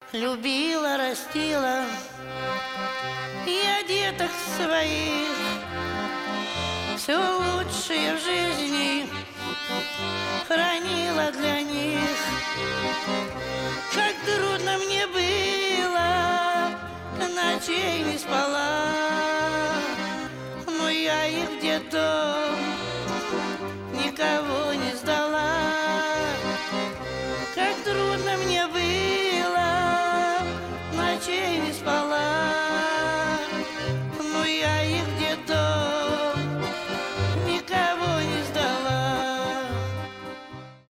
live
душевные , аккордеон , грустные